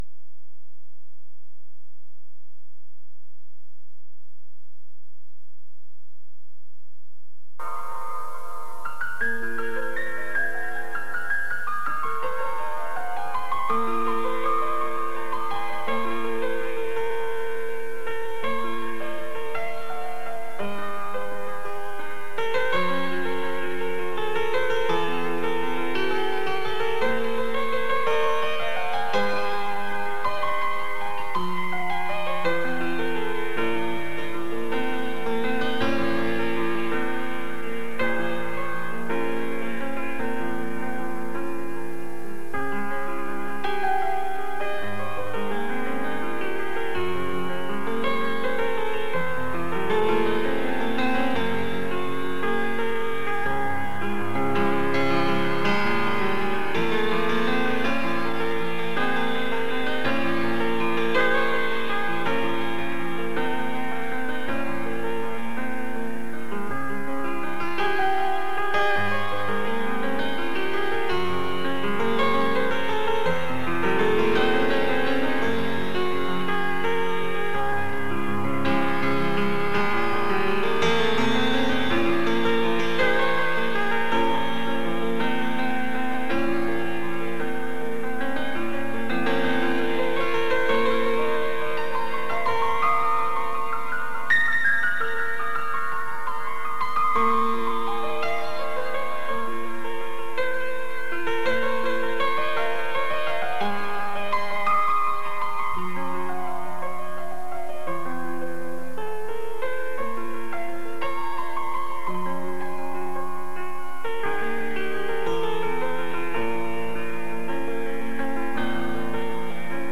G-Wave Detector Music